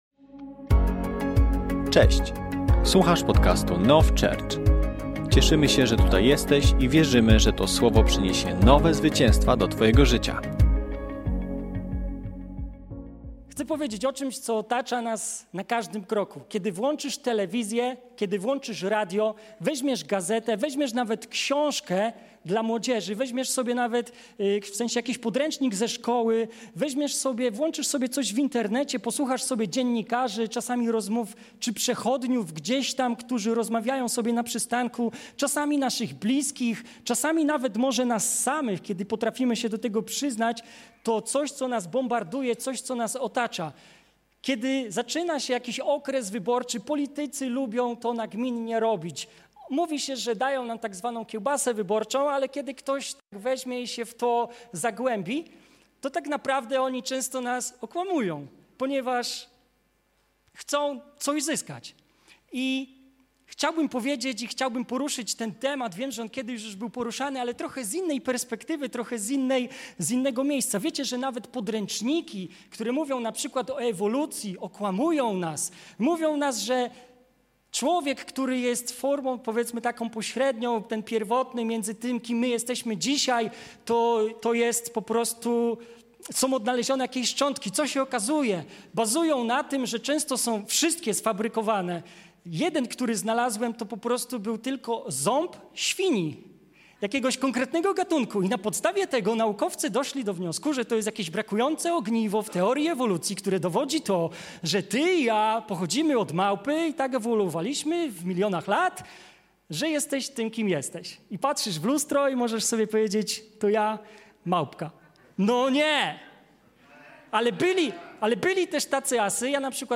Kazanie zostało nagrane podczas niedzielnego nabożeństwa NOF Church 16.02.2025 r. Download episode Share Share Copy URL Subscribe on Podcast Addict